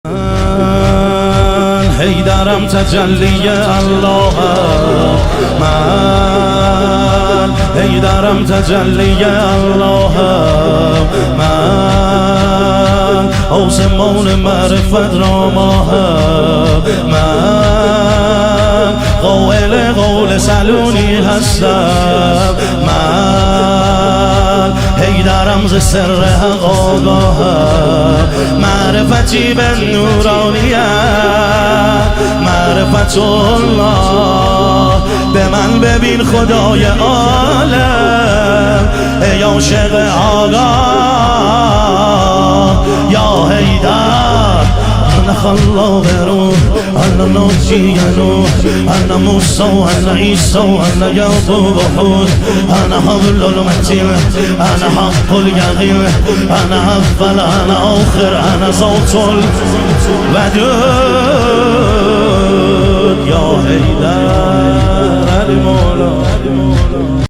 مداحی پر شور